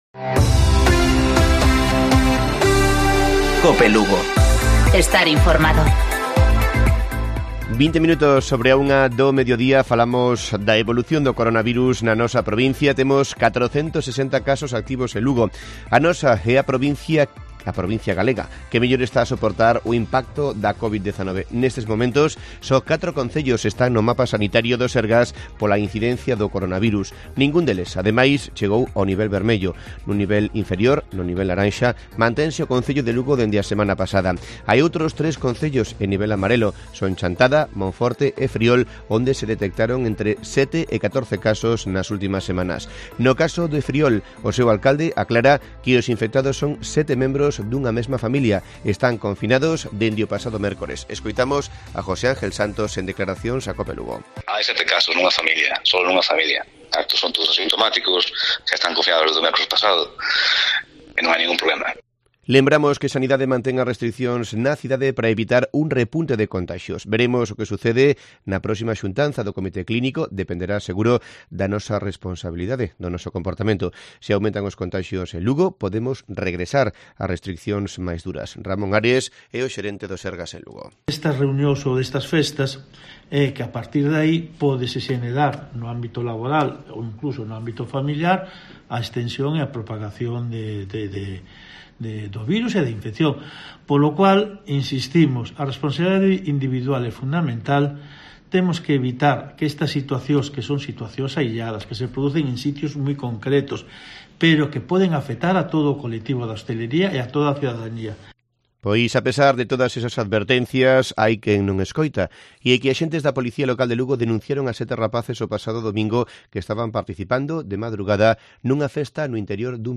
Informativo Provincial de Cope Lugo. 13 de octubre. 13:20 horas